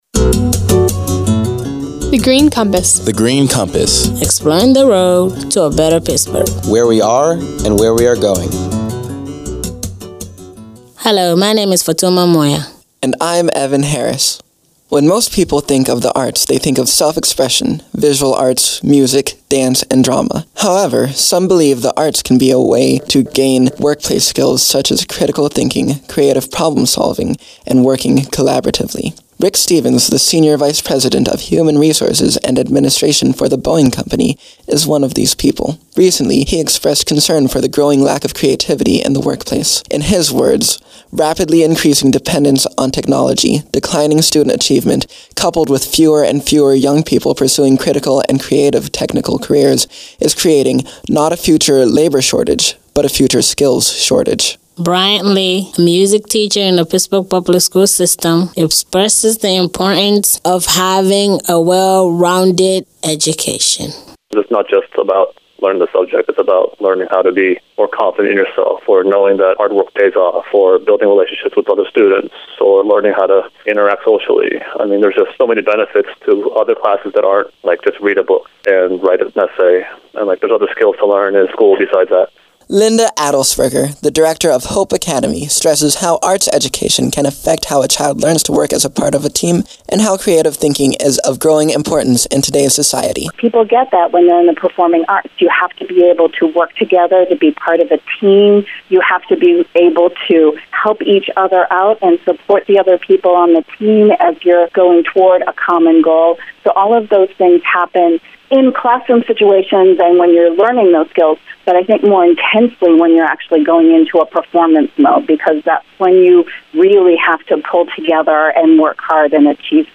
In July 2012, twenty-three students about to enter their first year of college created these nine radio features as Summer Youth Philanthropy Interns at The Heinz Endowments.
interview